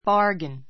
bargain A2 bɑ́ː r ɡin バ ーゲン 名詞 ❶ 取り引き; 契約 けいやく make a bargain with ～ make a bargain with ～ ～と取り引きする ❷ お買い得品, 特売品 make a good [bad] bargain make a good [bad] bargain 得[損]な買い物をする My new bike was a great bargain.